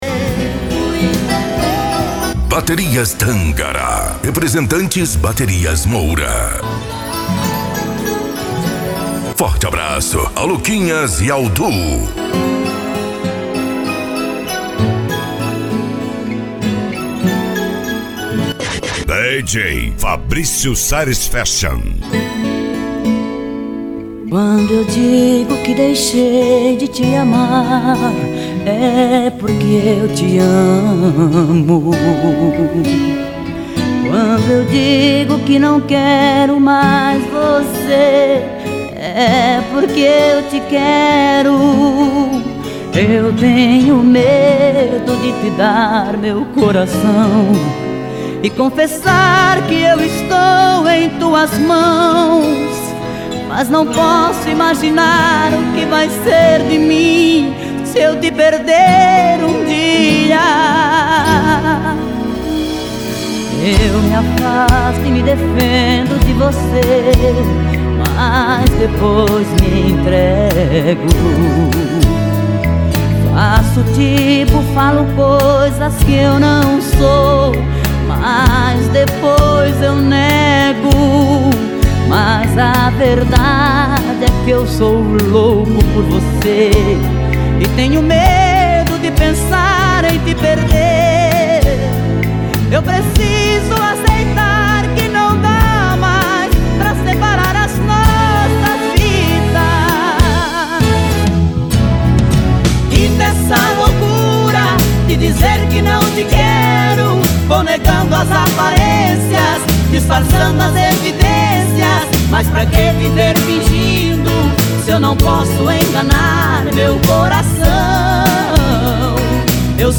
Modao